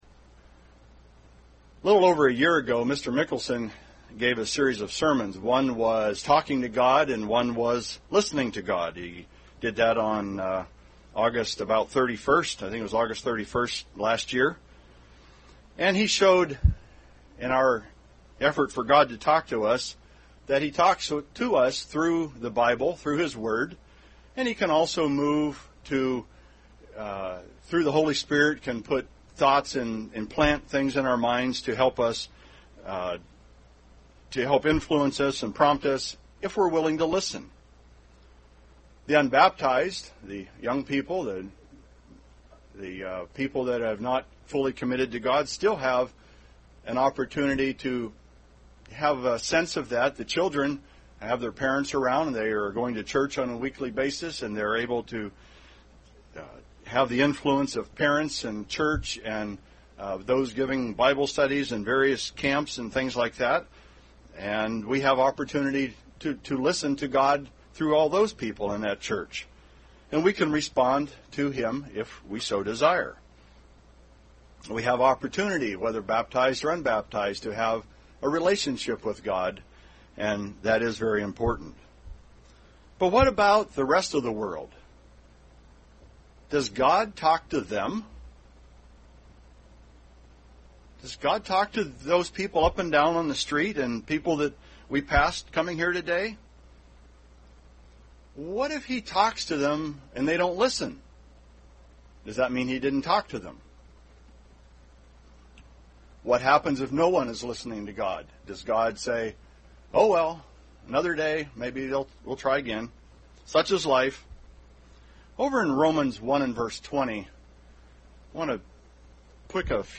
Sermon on Saturday, September 20, 2014 in Kennewick, Washington